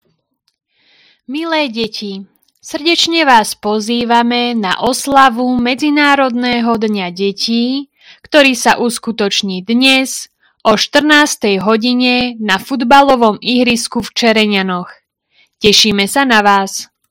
Hlásenie obecného rozhlasu – Deň detí dnes o 14:00